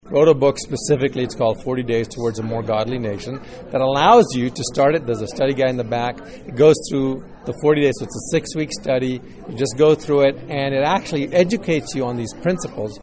Speaking in the high school auditorium,  author